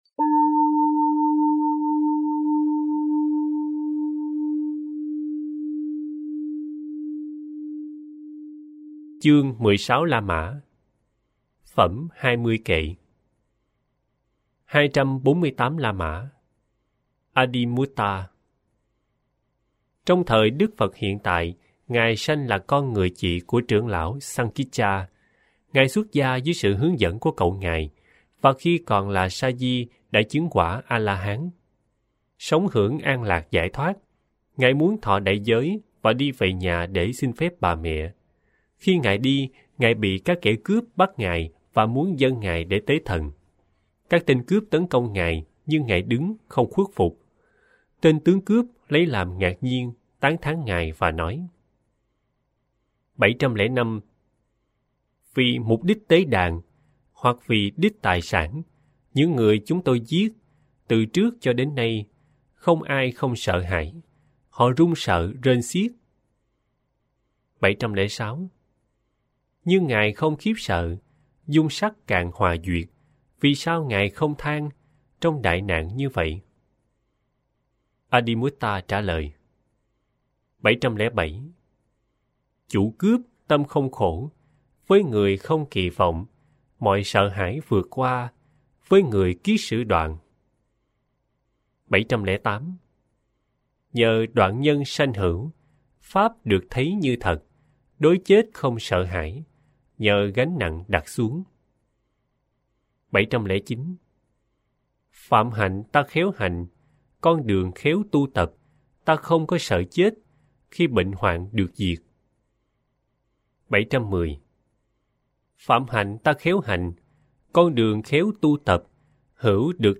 30 K Tiểu Bộ tập 2 – Trưởng lão Tăng kệ – Chương 16 – Phẩm Hai Mươi Kệ giọng miền Nam.mp3